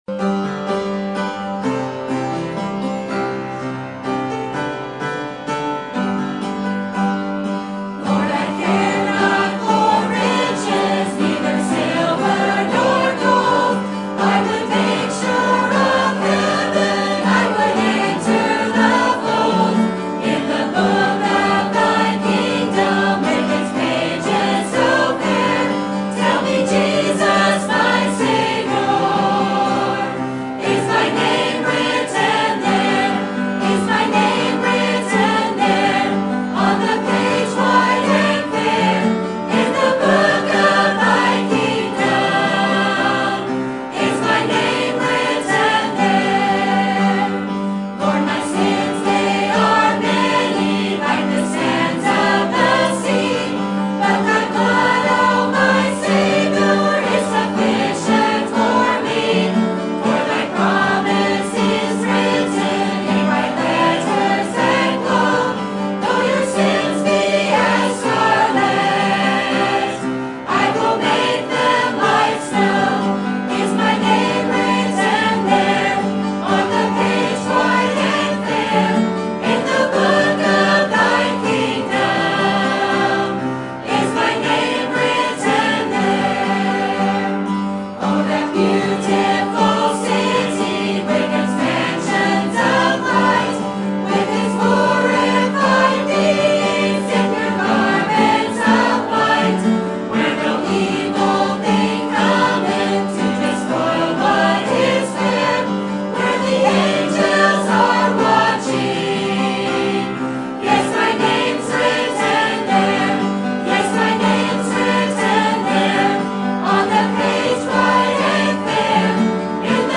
Sermon Topic: General Sermon Type: Service Sermon Audio: Sermon download: Download (31.86 MB) Sermon Tags: Acts Repentance Jews Gentiles